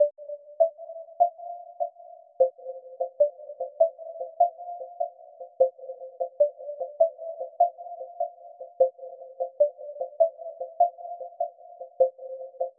昏昏欲睡的钟声
Tag: 150 bpm Trap Loops Bells Loops 2.15 MB wav Key : Unknown FL Studio